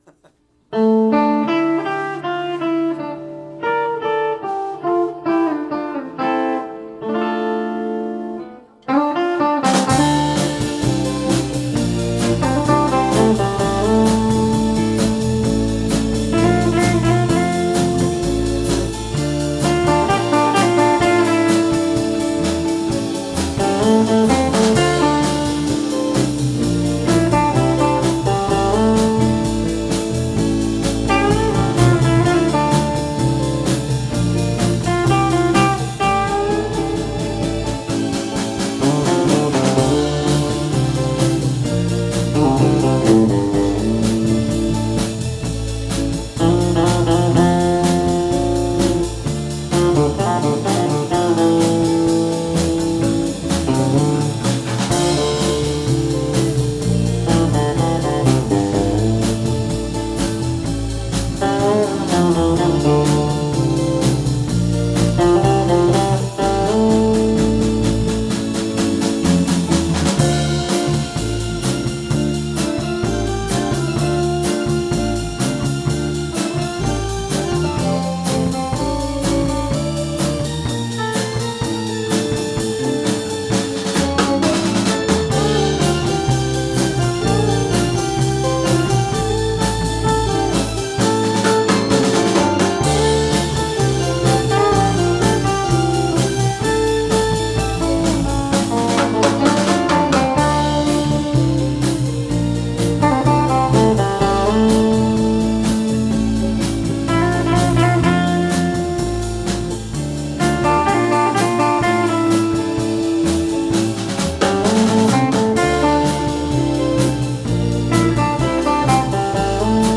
場所：ベンチャーズハウス「六絃」
メンバーの皆さん前を向いて演奏しましょう